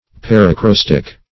Search Result for " paracrostic" : The Collaborative International Dictionary of English v.0.48: Paracrostic \Par`a*cros"tic\, n. [Pref. para- + acrostic.] A poetical composition, in which the first verse contains, in order, the first letters of all the verses of the poem.